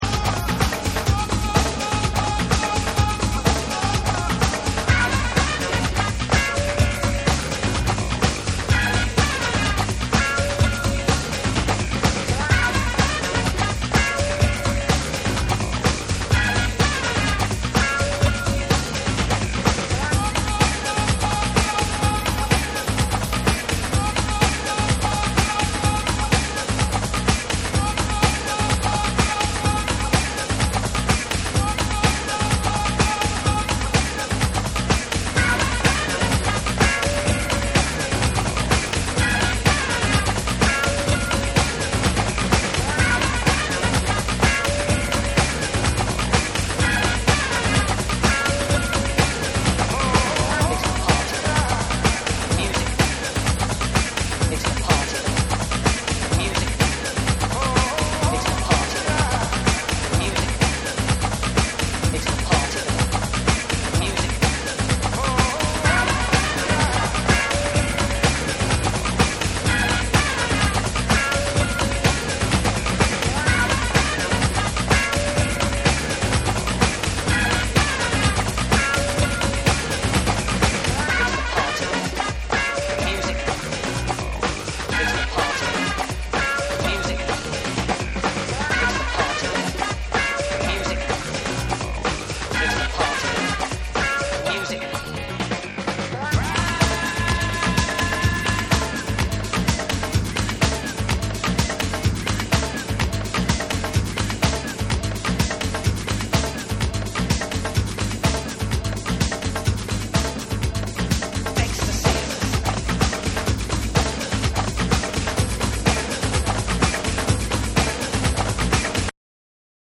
TECHNO & HOUSE